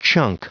Prononciation du mot chunk en anglais (fichier audio)
Prononciation du mot : chunk